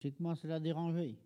Bouin ( Plus d'informations sur Wikipedia ) Vendée
Catégorie Locution